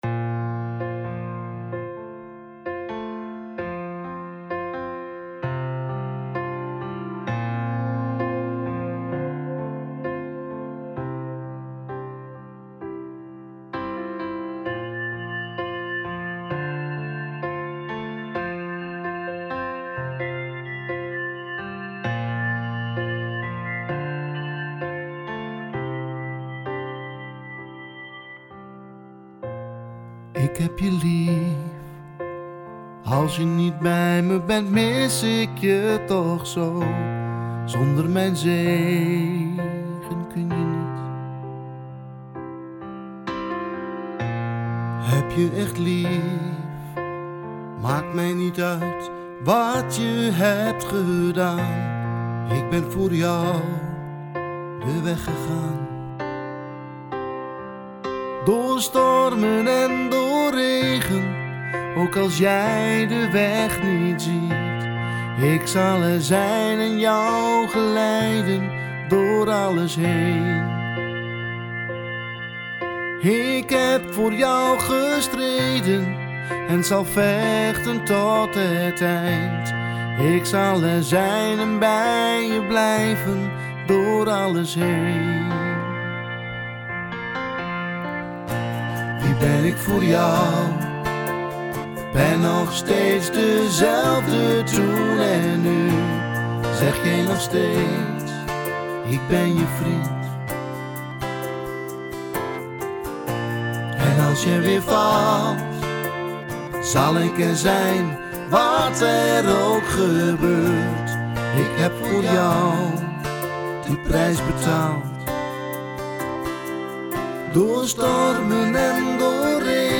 Vocale opname